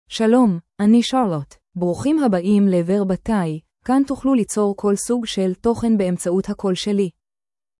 CharlotteFemale Hebrew AI voice
Charlotte is a female AI voice for Hebrew (Israel).
Voice sample
Listen to Charlotte's female Hebrew voice.
Female
Charlotte delivers clear pronunciation with authentic Israel Hebrew intonation, making your content sound professionally produced.